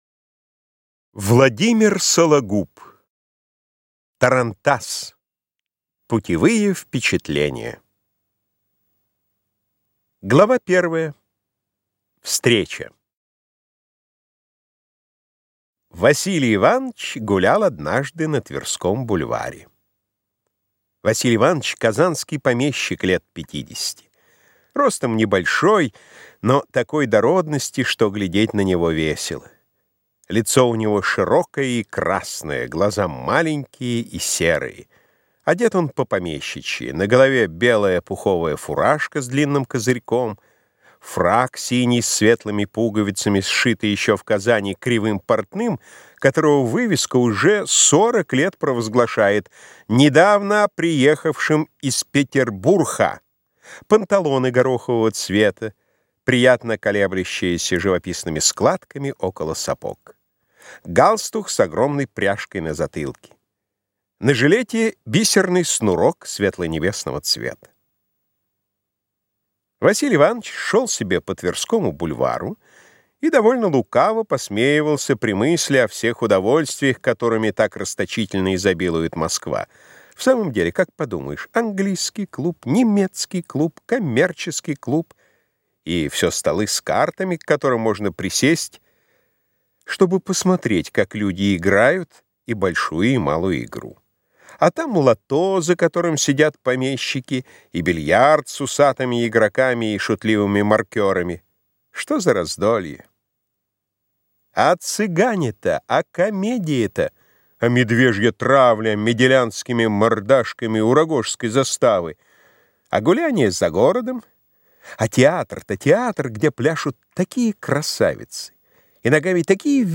Аудиокнига Тарантас | Библиотека аудиокниг